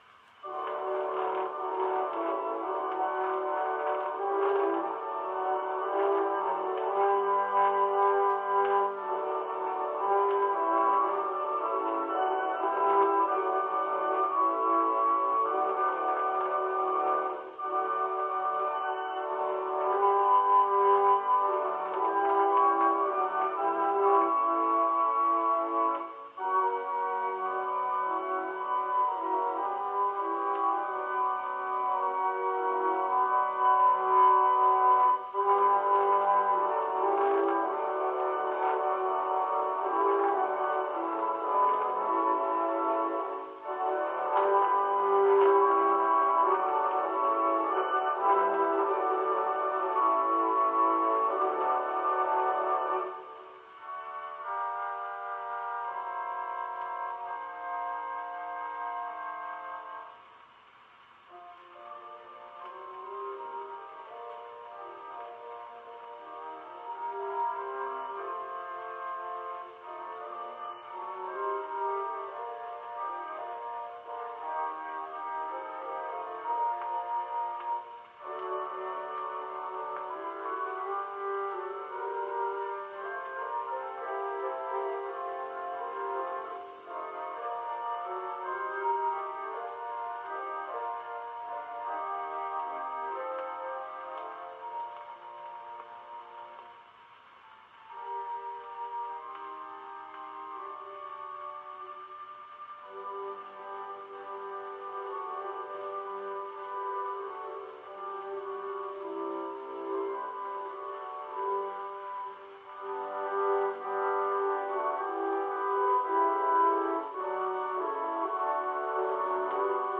Pipe Organ Solo